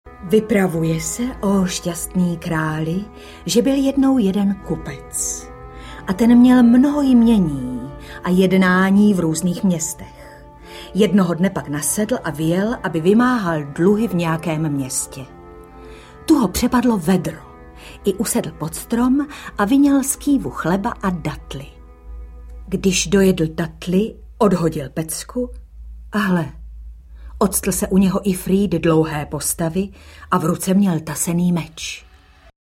Pohádky tisíce a jedné noci audiokniha
Výběr z mimořádně úspěšného rozhlasového cyklu z let 1995-1998, obsahuje úvod, příběh noci 1., 2., 52., 999., 1000. a 1001.